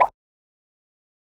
Perc (Lights).wav